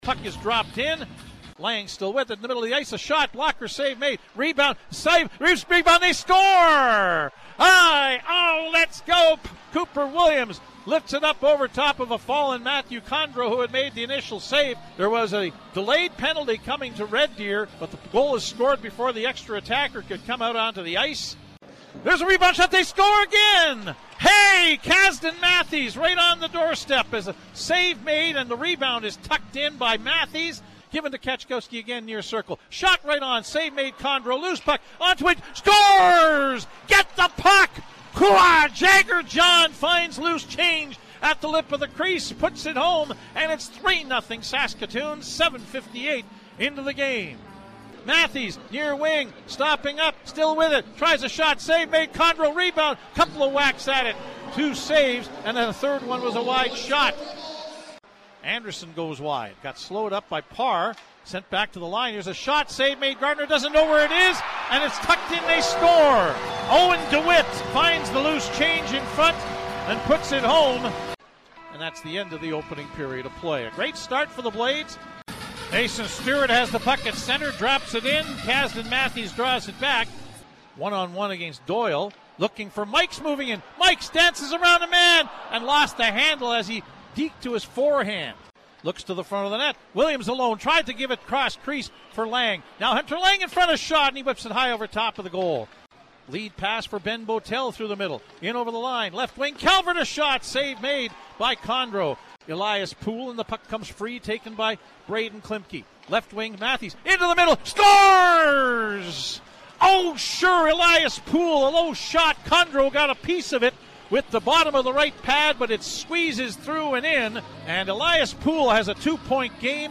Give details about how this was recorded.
Following are the audio highlights from CJWW’s broadcast